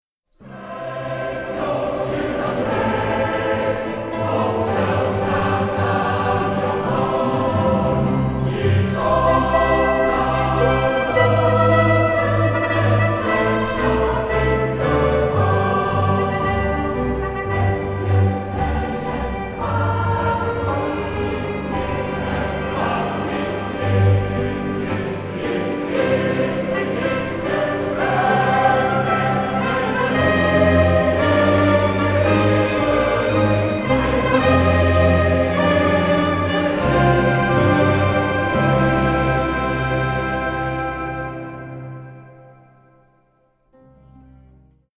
Oratorium over het leven van Mozes